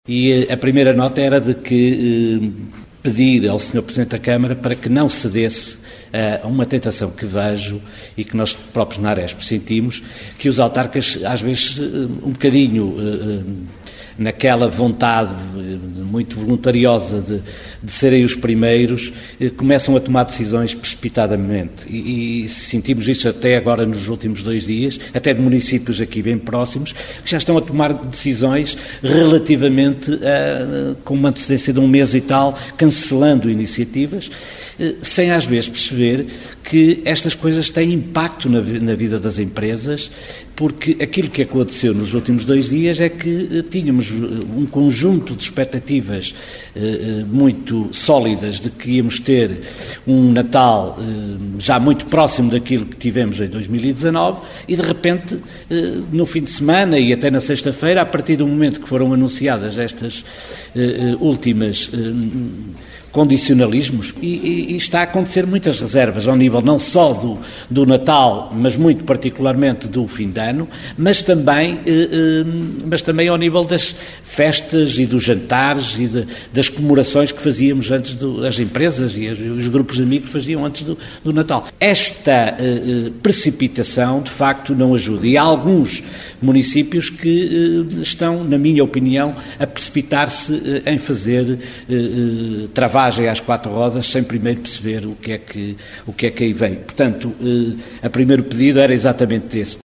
Este responsável falava na conferência de imprensa de apresentação das atividades de Natal da iniciativa do Município de Viseu e das quais a AHRESP é parceira, tal como a Associação Comercial do Distrito de Viseu (ACDV).